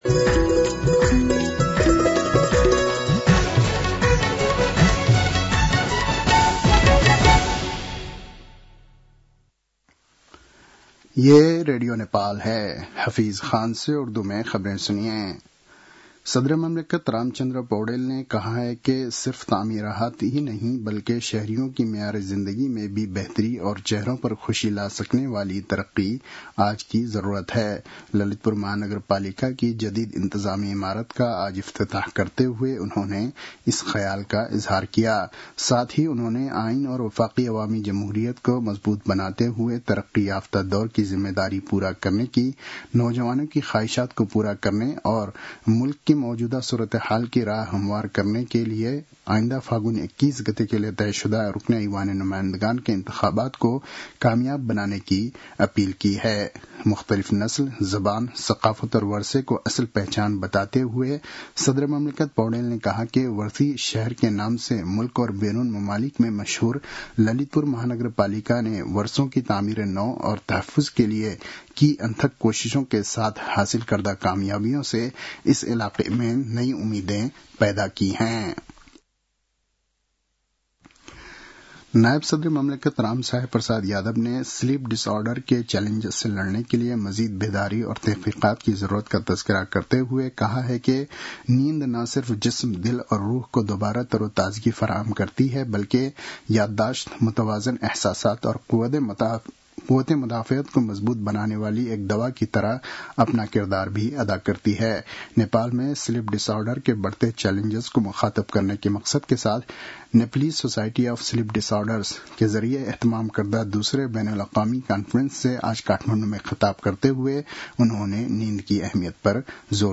उर्दु भाषामा समाचार : २० मंसिर , २०८२